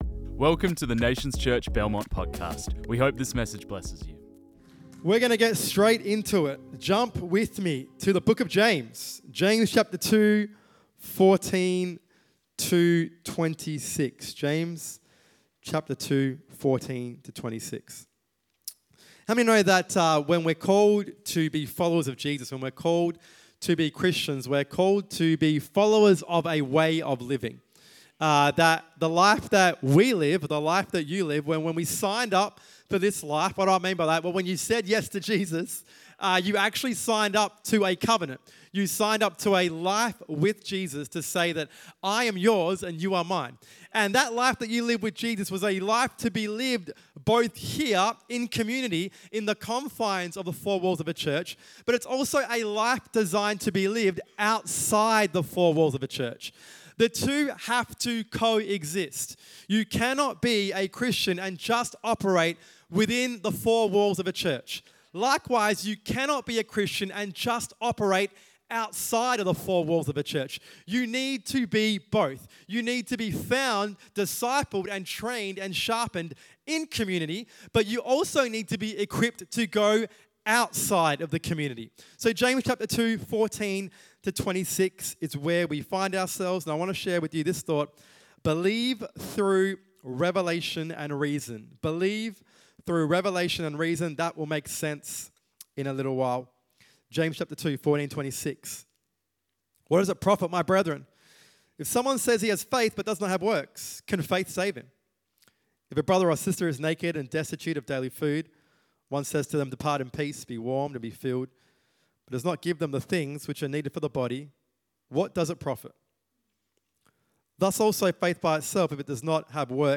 This message was preached on 23rd of March 2025